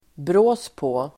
Ladda ner uttalet
Uttal: [br'å:s på:]